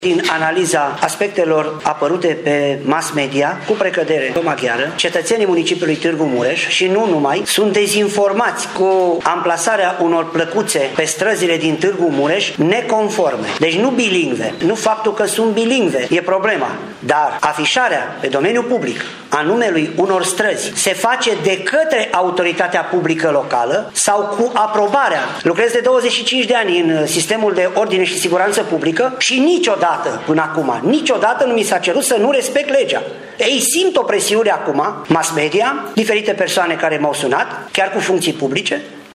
Declarația aparține șefului Poliției Locale, Valentin Bretfelean, care a susținut astăzi o conferință de presă pe acest subiect, mult discutat în ultimul timp la Tîrgu-Mureș.